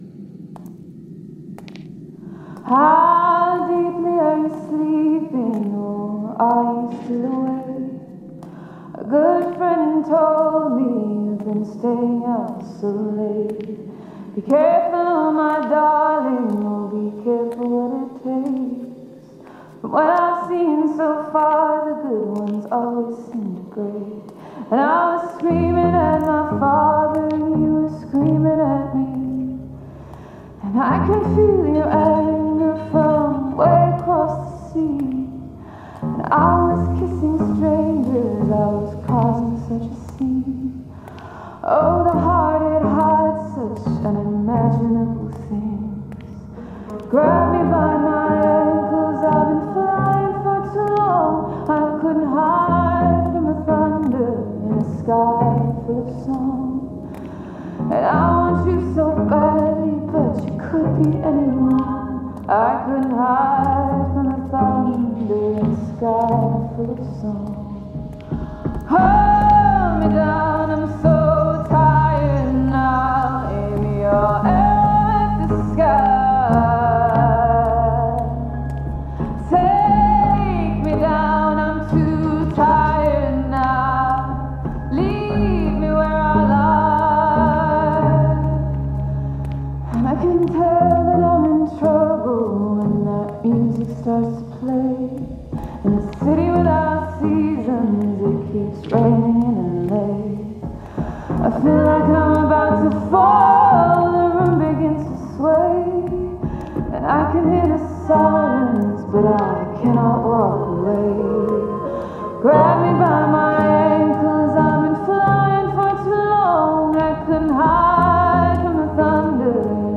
playing in a large, empty cathedral